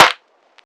SNARE.28.NEPT.wav